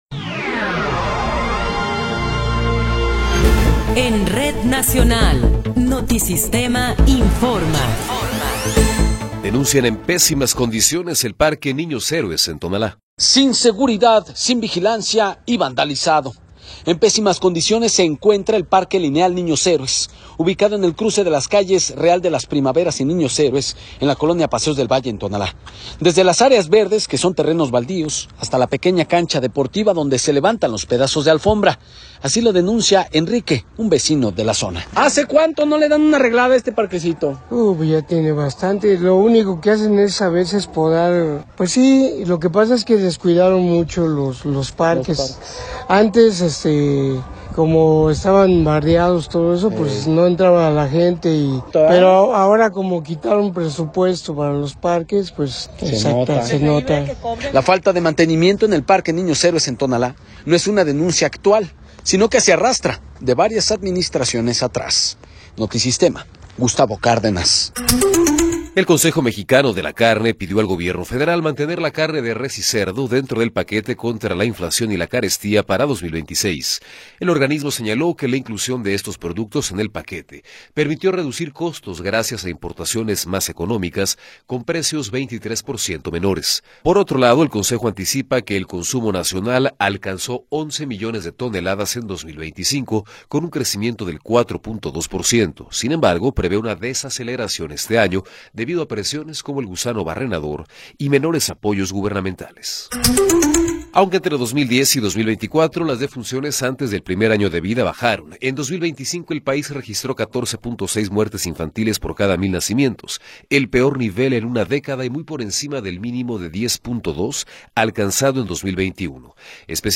Noticiero 18 hrs. – 3 de Enero de 2026